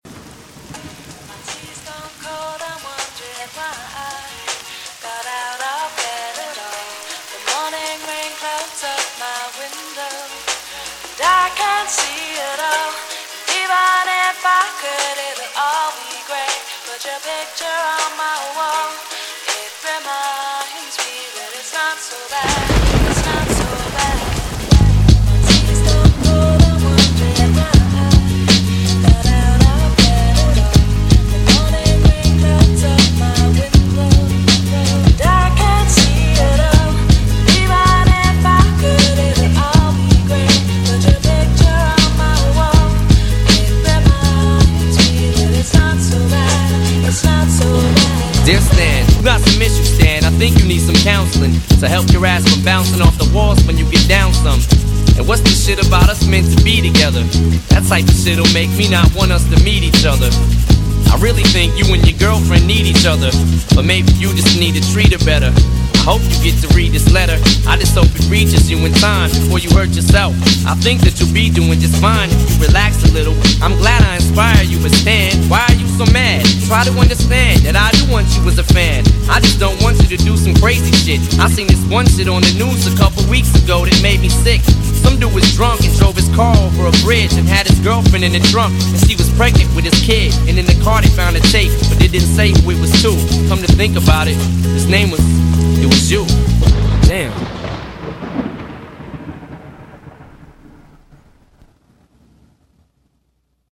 BPM80--1
Audio QualityPerfect (High Quality)